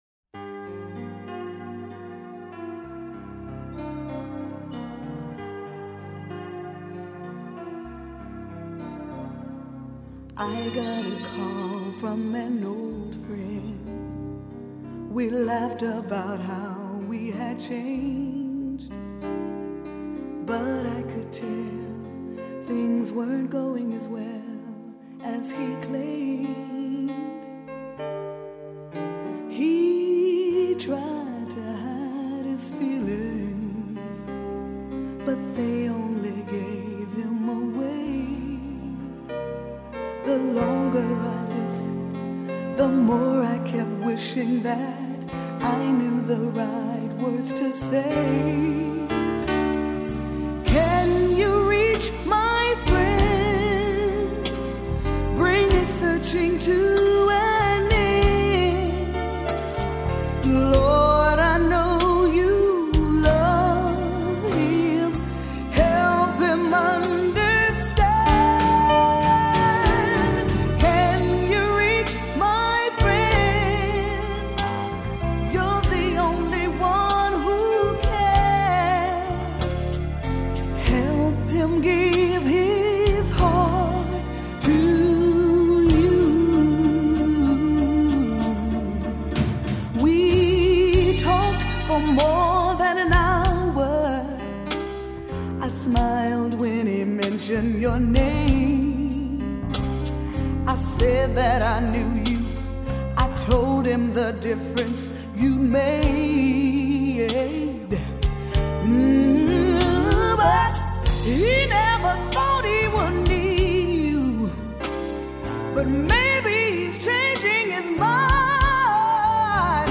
Genre: CCM.